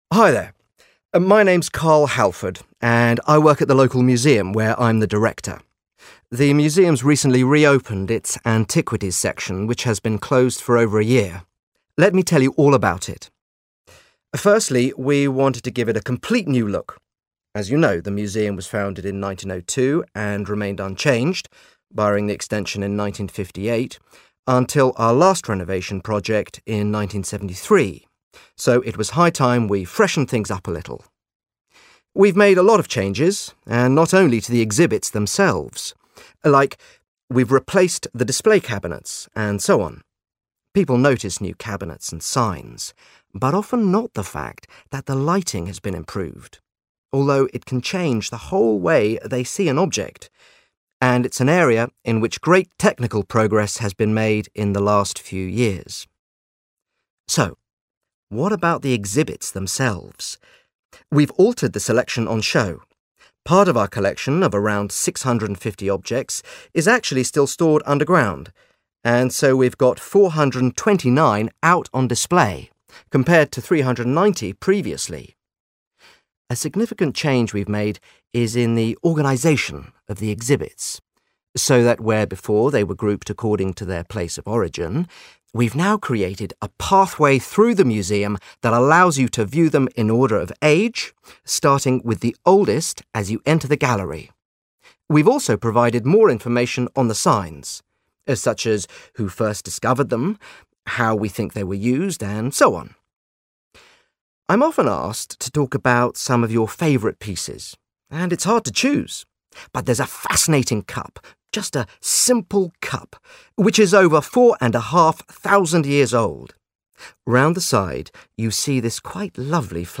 You will hear an interview with a man